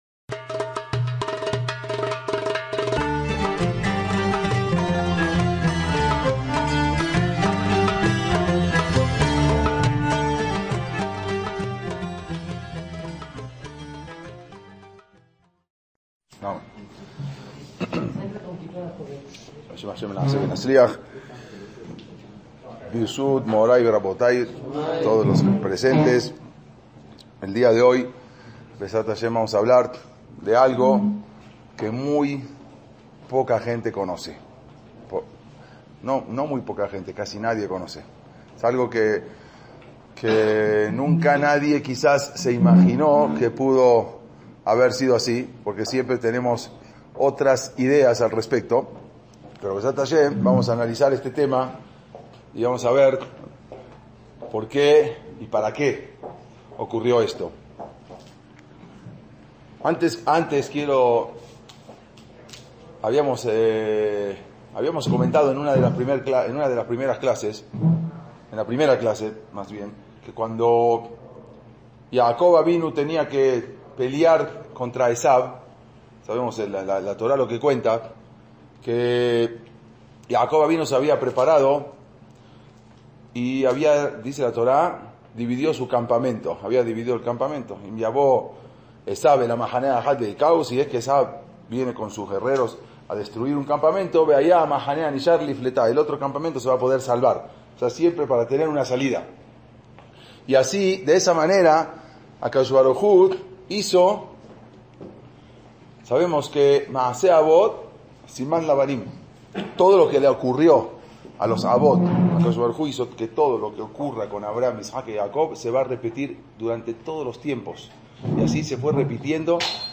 ACTOS EN DIRECTO - Uno de los capítulos menos conocidos de la historia del continente americano es la presencia de judíos entre los piratas que asolaban las costas del Caribe en la Edad Moderna.